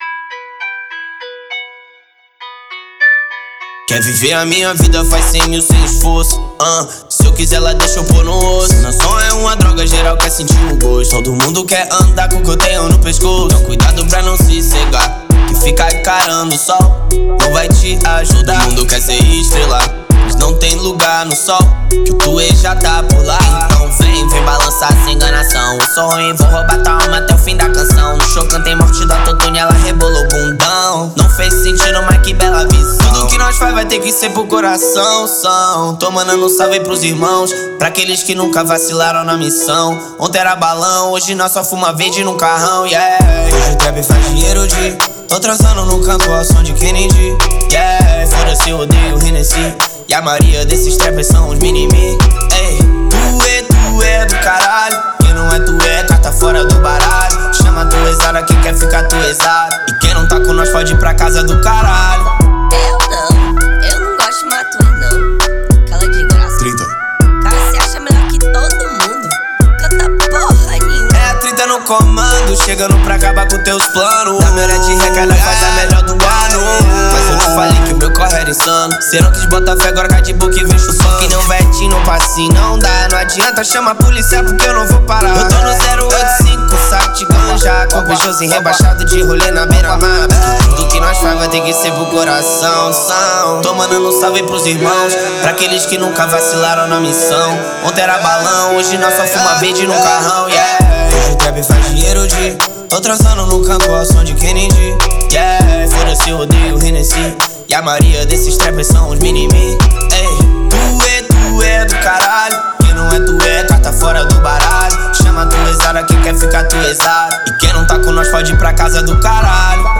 2025-04-09 12:02:33 Gênero: Trap Views